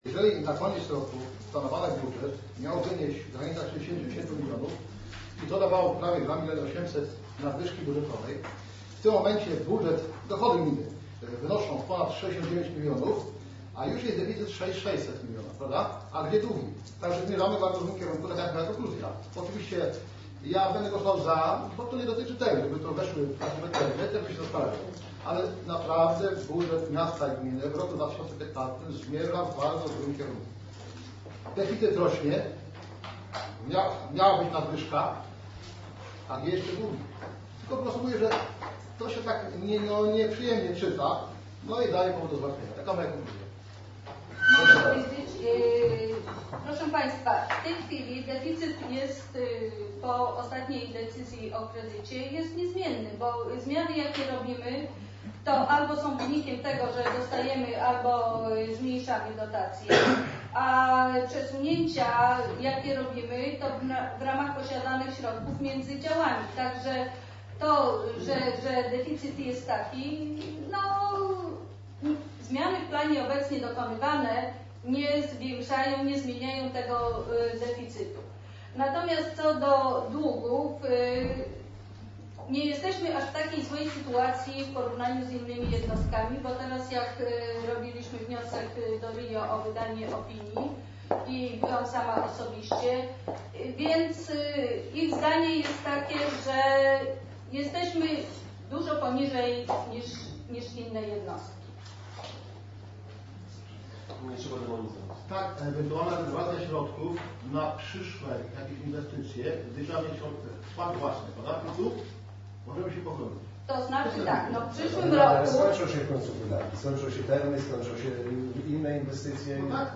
Posiedzenie połączonych komisji Rady Miasta Lidzbark Warmiński odbyło się 15 czerwca 2015r. w sali konferencyjnej Urzędu Miejskiego. Najważniejszą sprawą do omówienia, było udzielenie Burmistrzowi absolutorium z wykonania budżetu za 2014r.